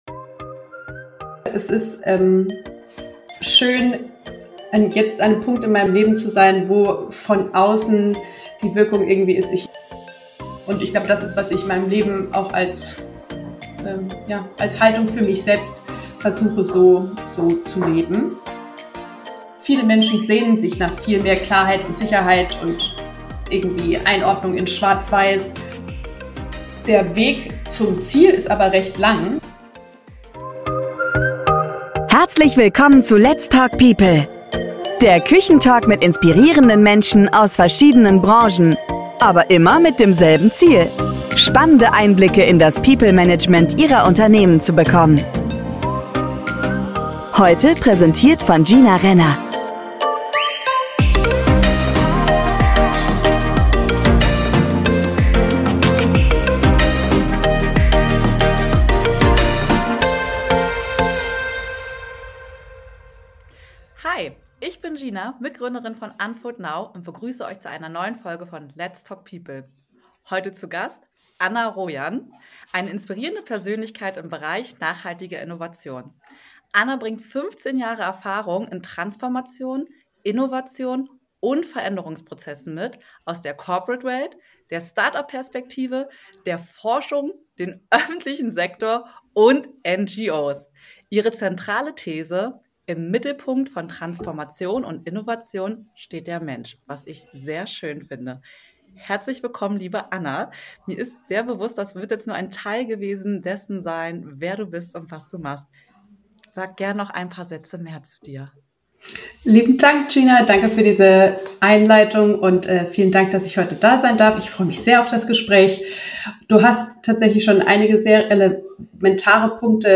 Ein Gespräch über Empowerment, Diversität und echte Veränderung.